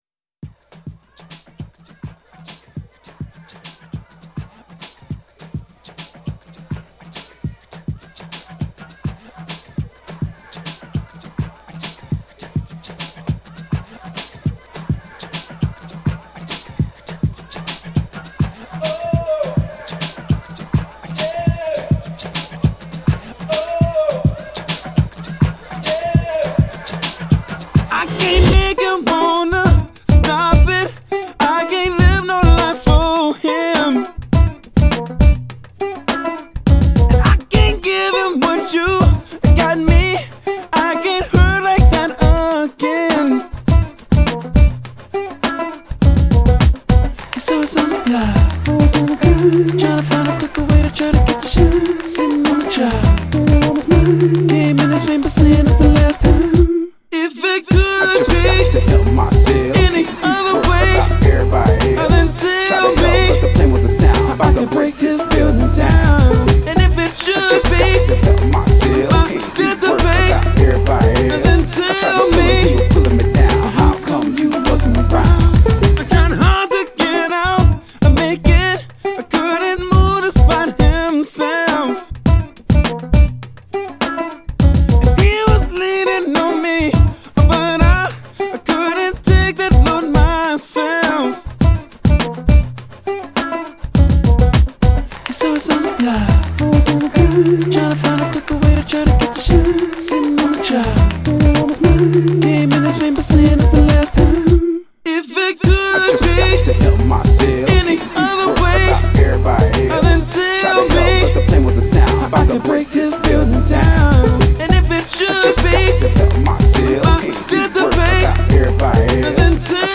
incorporating guitar, banjo, cello, and turntables